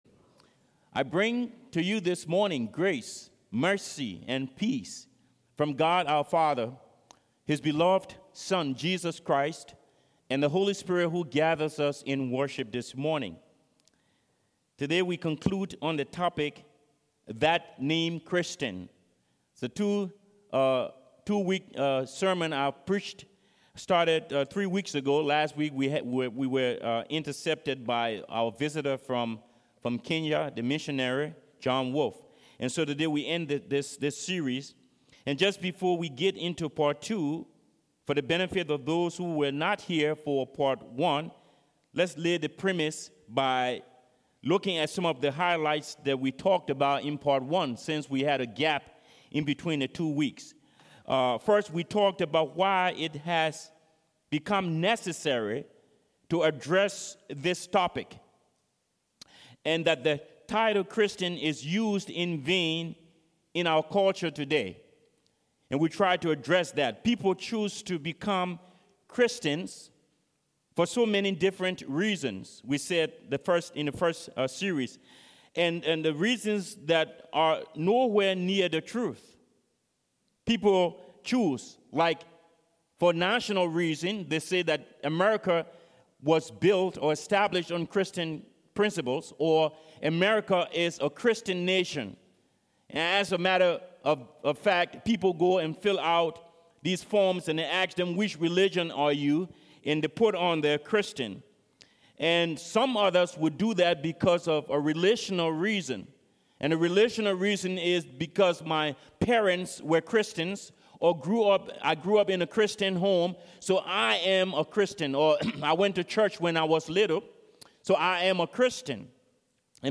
Service Type: Sermons